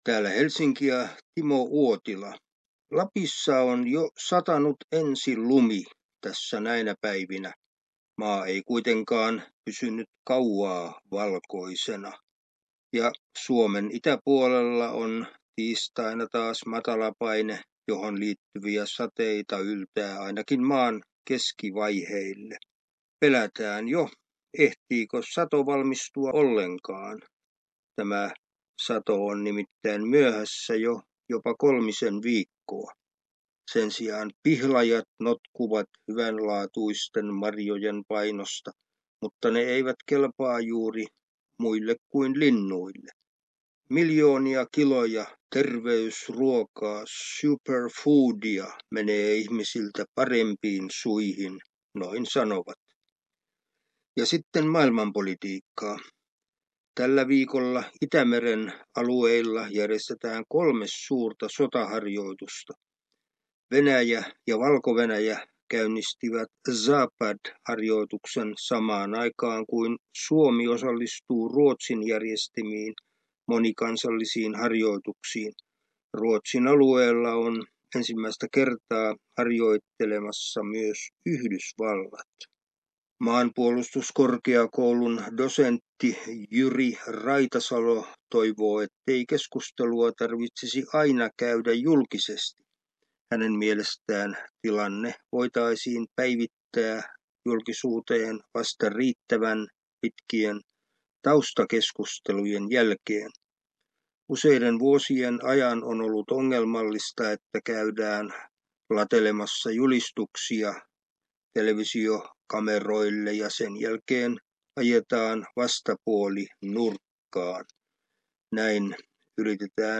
säännöllinen ajankohtaisraportti Suomesta